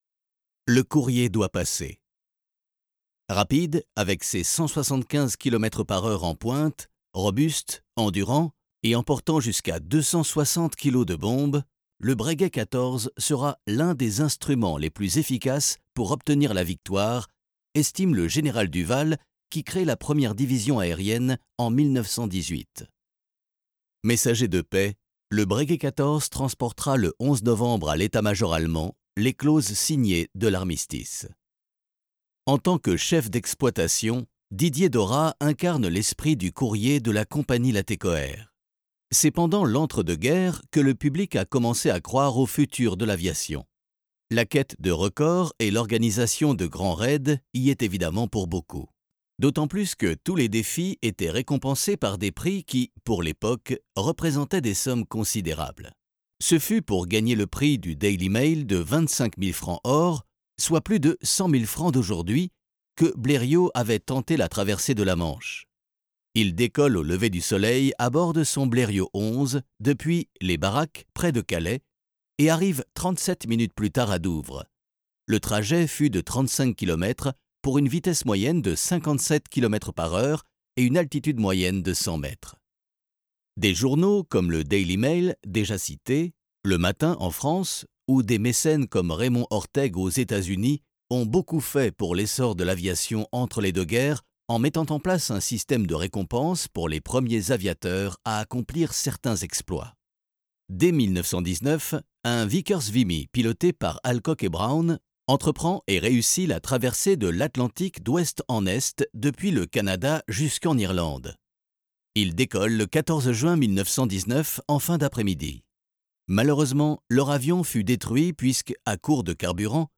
Male
Corporate, Assured, Friendly, Sarcastic, Smooth, Warm
Microphone: Neumann U87
Audio equipment: Manley voxbox , avalon , RME Fire face